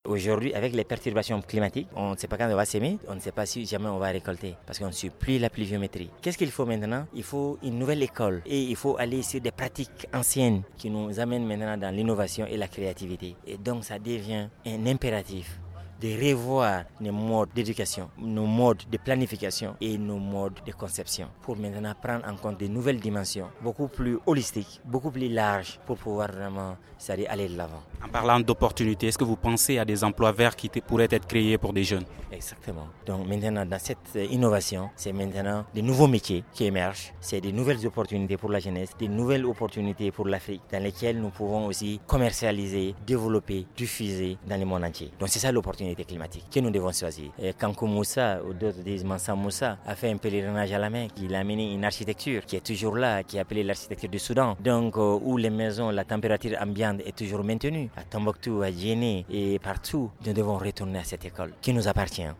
La septième édition du Forum environnemental national s’est ouverte ce jeudi 15 février à Bamako.